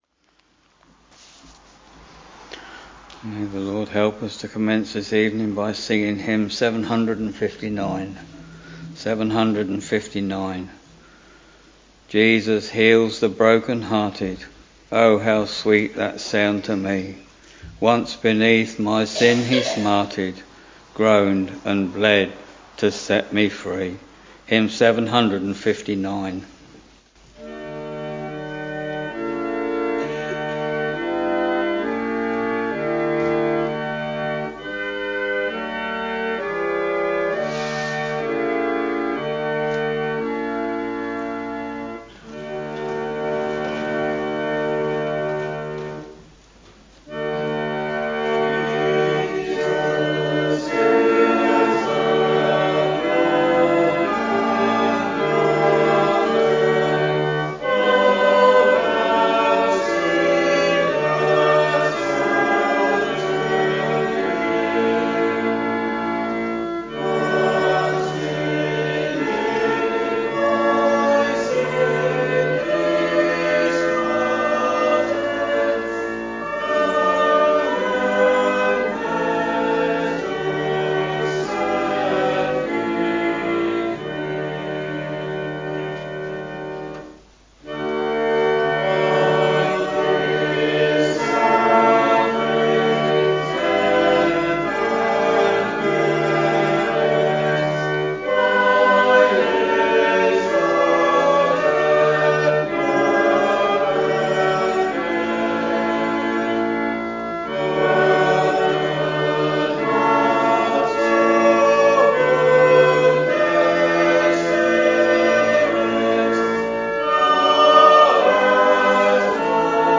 Week Evening Service